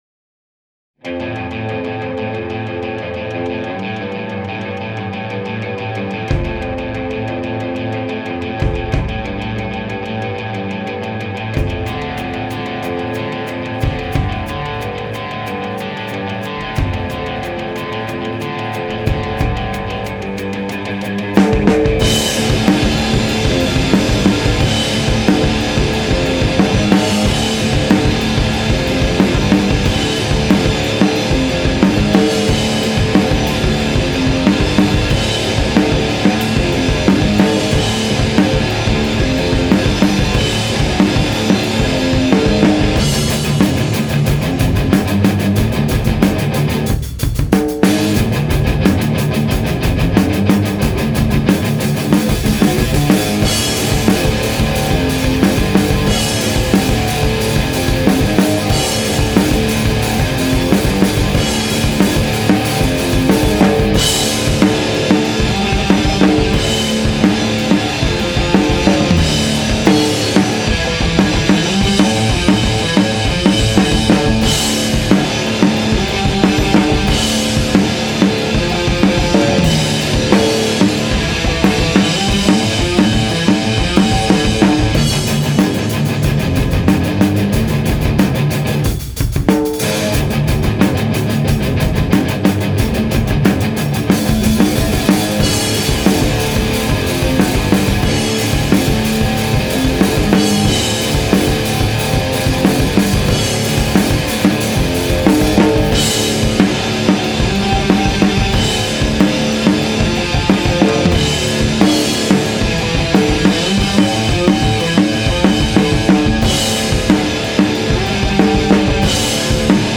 propone atmosfere scure e aggressive